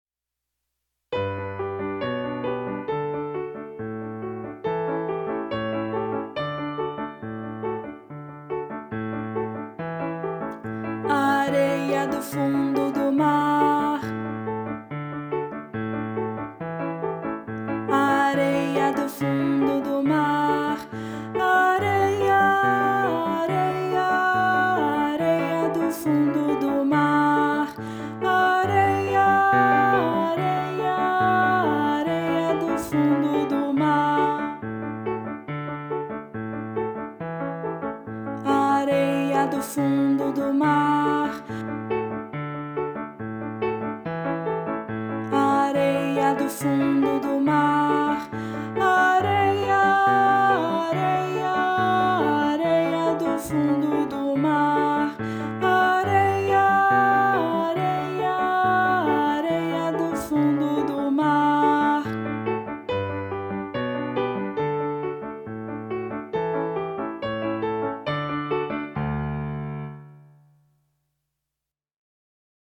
Voz Guia 2